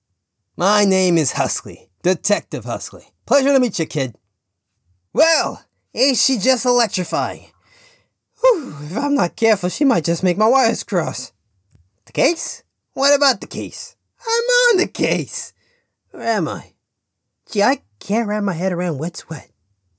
English (United Kingdom)
Young Adult